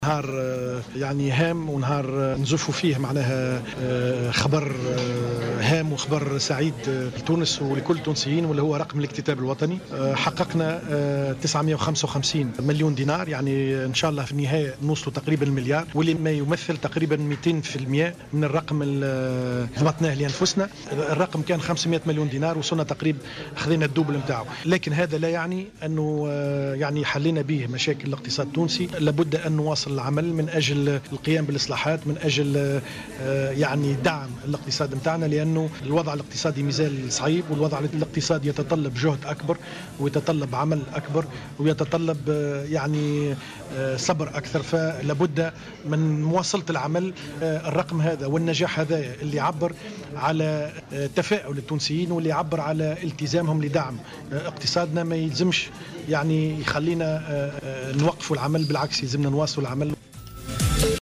Le ministre des Finances et de l'Economie Hakim Ben Hammouda a annoncé aujourd'hui, lors d'une conférence de presse, que la somme de 955 millions de dinars a été collectée dans le cadre de l'emprunt obligataire et qui se déroule du 12 mai au 27 juin 2014.